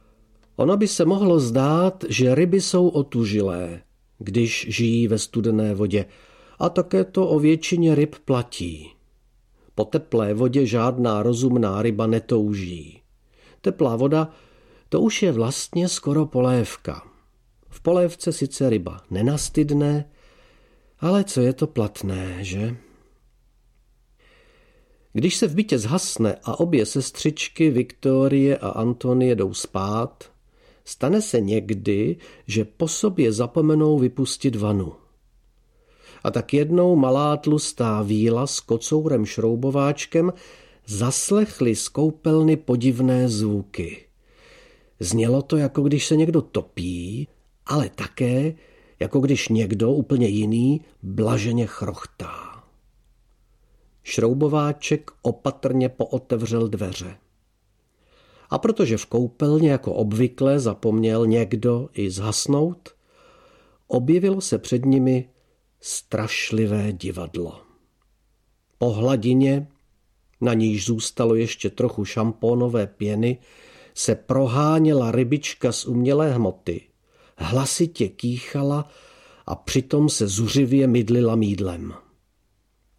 Pohádky o Malé tlusté víle audiokniha
Audiokniha Pohádky o Malé tlusté víle - obsahuje pohádky, které čte jejich autor a každý příběh doprovodí písničkou, reagující ve zkratce na předchozí událost.
Ukázka z knihy
• InterpretJiří Dědeček